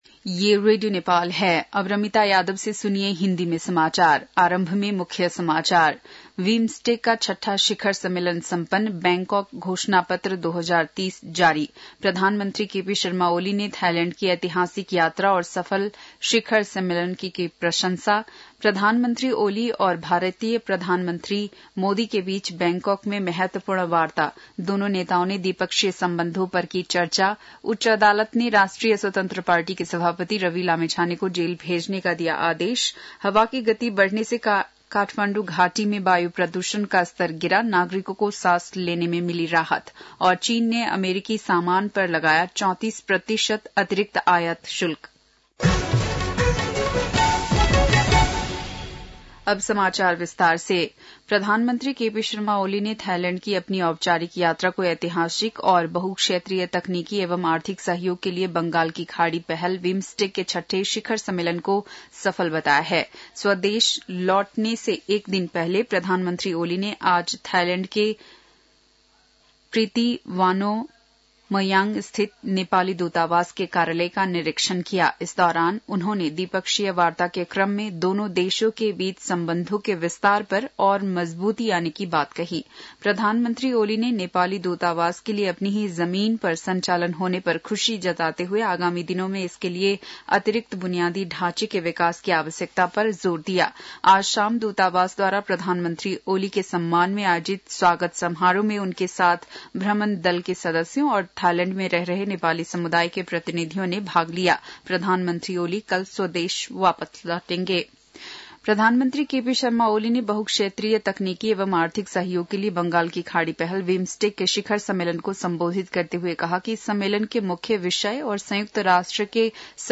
बेलुकी १० बजेको हिन्दी समाचार : २२ चैत , २०८१